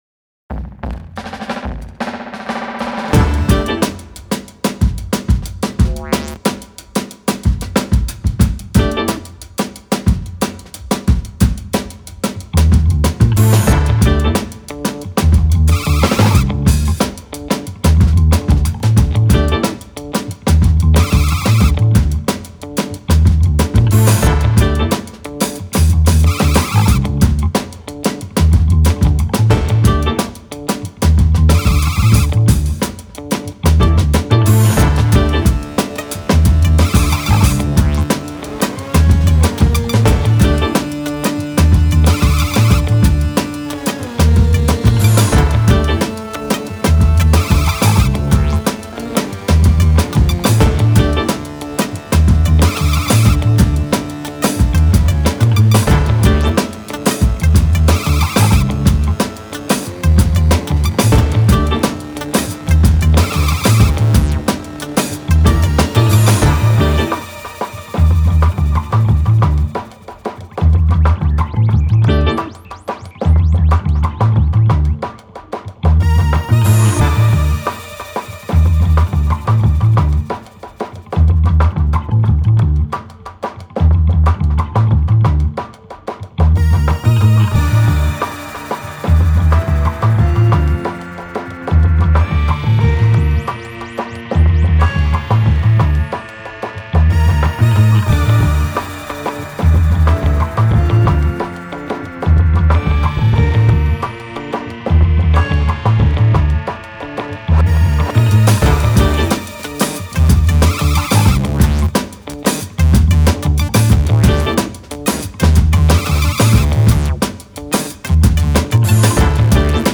Funky electronic street beat.